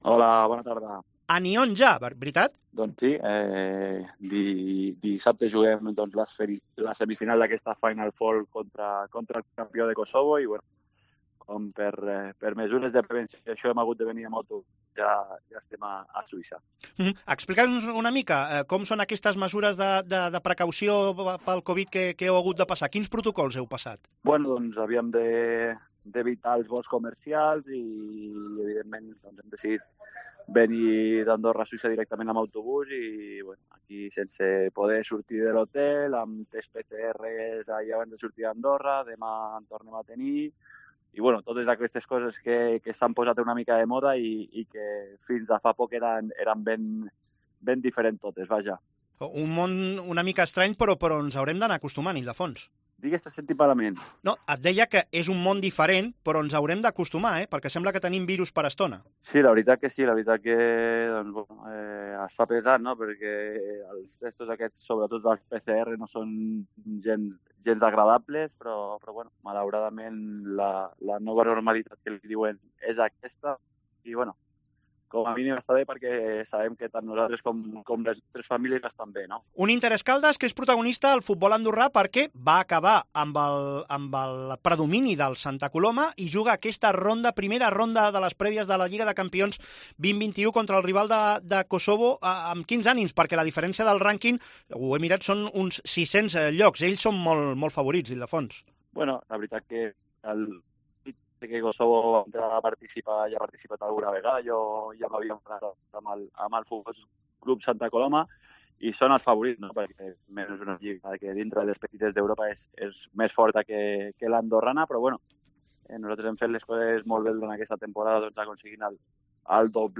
Entrevista a Ildefons Lima a Esports COPE